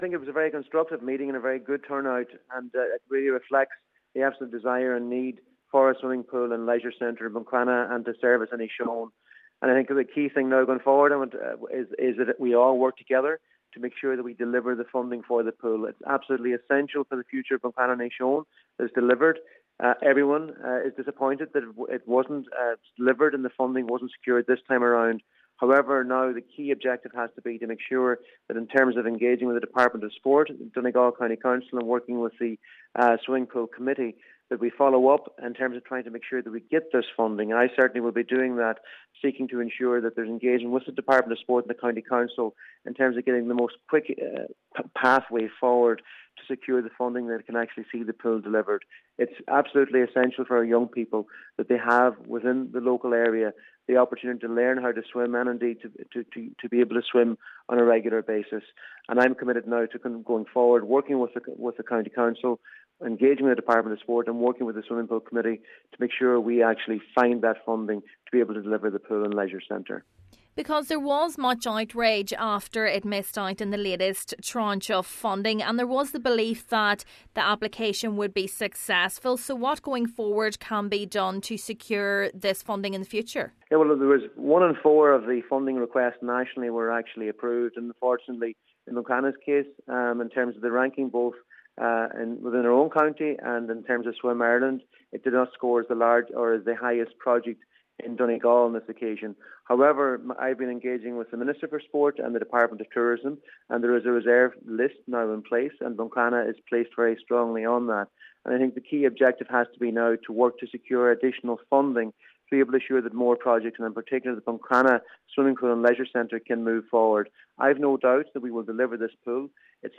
Minister McConalogue says its important joint efforts are made to get the project the funding it requires: